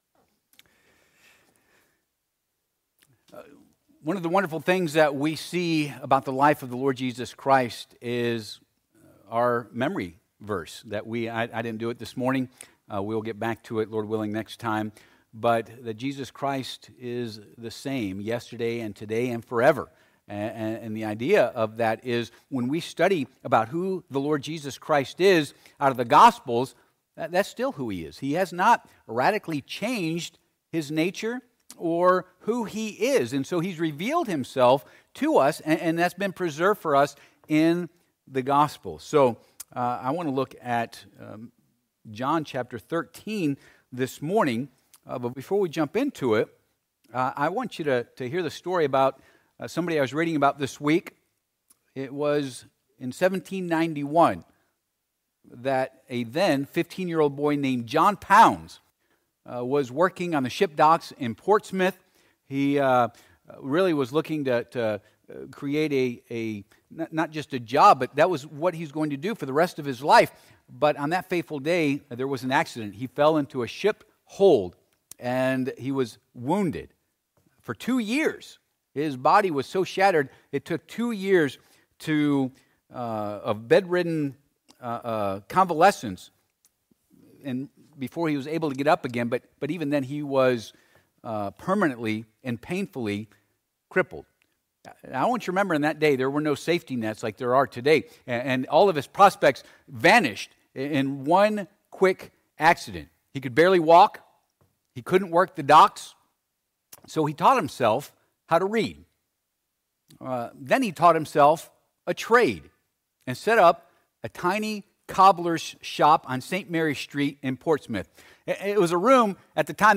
2026 Theme Passage: John 13:1-17 Service Type: Sunday AM Topics